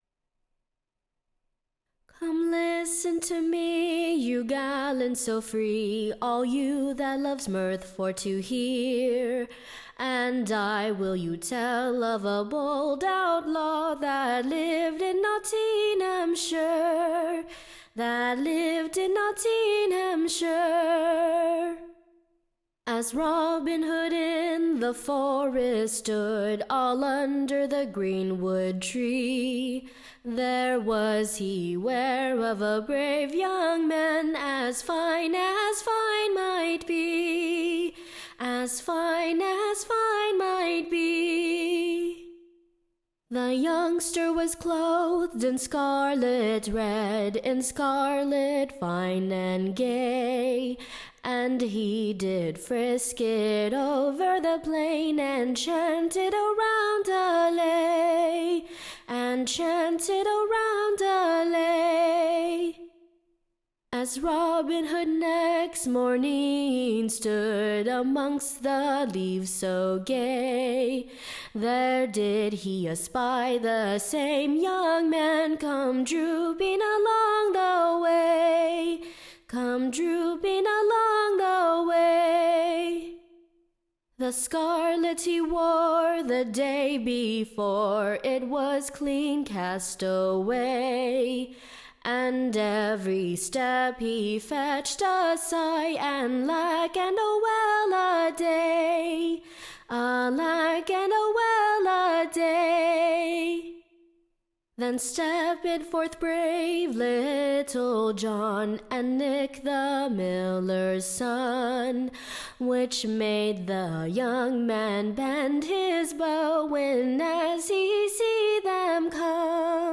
Ballad
Tune Imprint To a Pleasant Northern Tune, or, Robin Hood in the Green-wood stood.